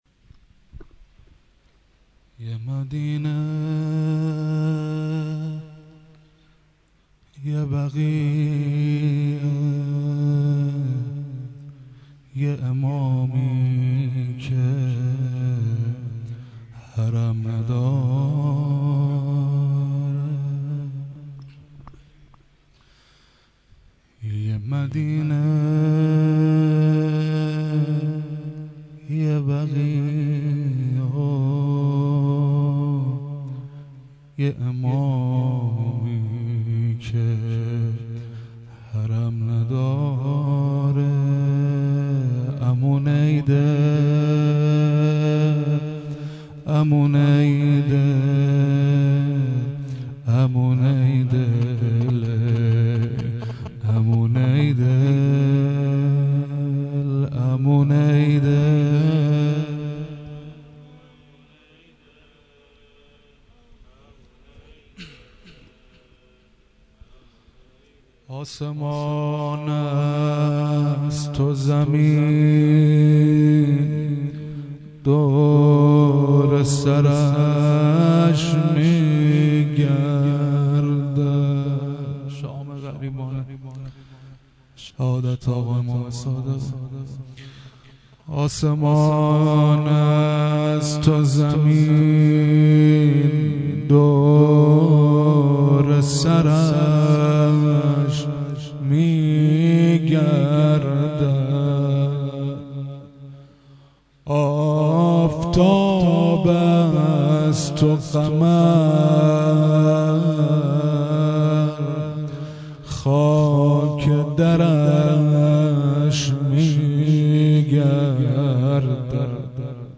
shahadat emam sadegh rozeh (2).mp3
shahadat-emam-sadegh-rozeh-2.mp3